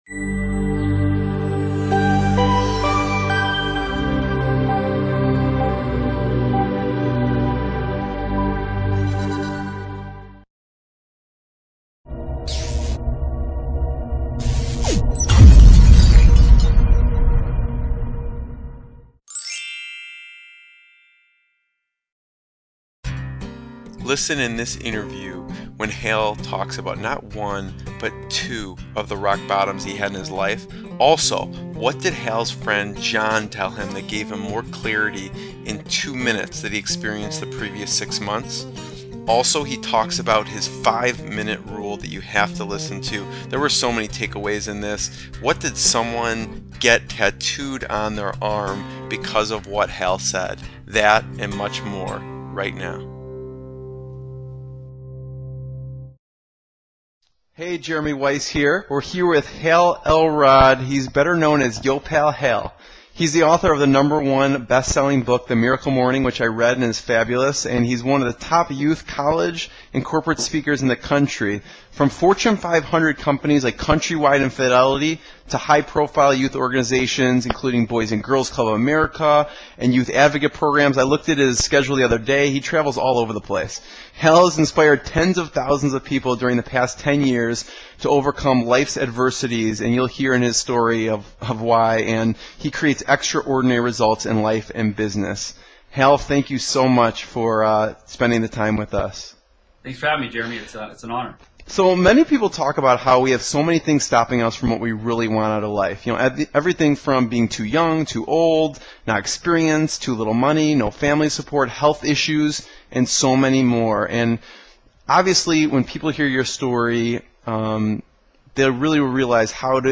What will you learn in this interview?